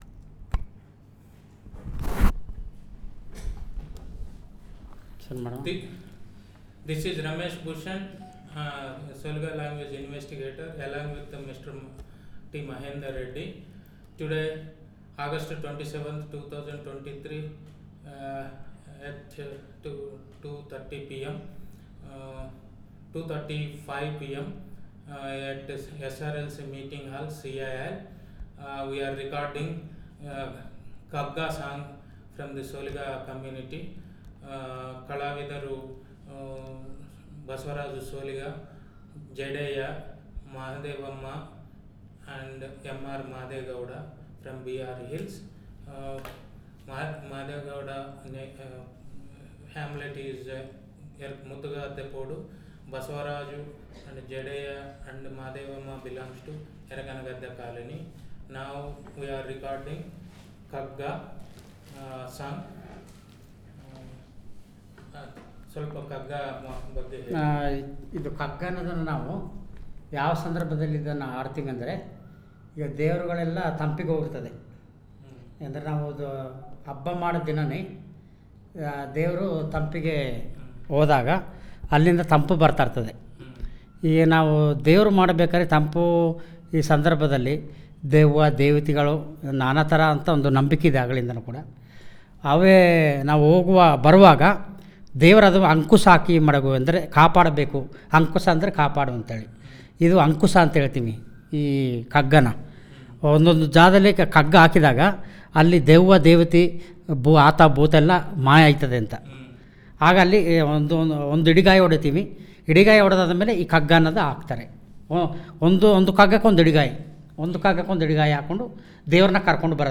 Elicitation of kagga song